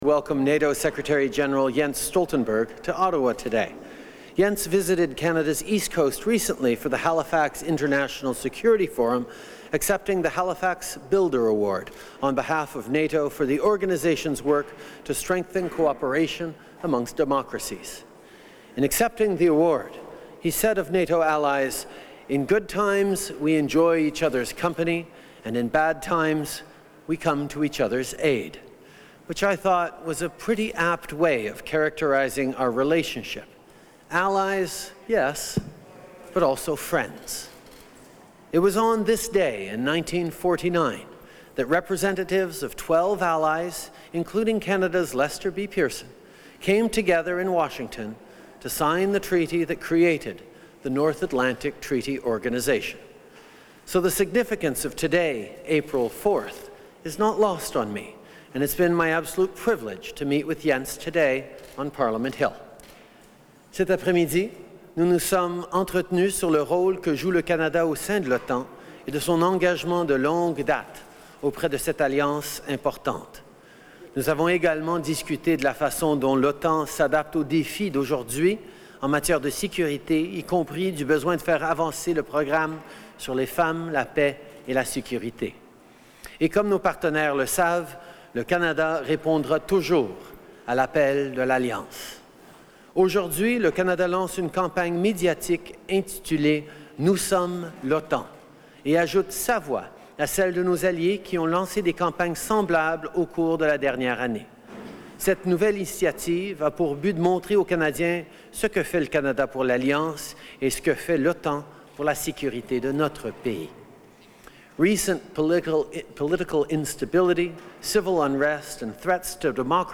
Joint press conference
with NATO Secretary General Jens Stoltenberg and the Prime Minister of Canada, Justin Trudeau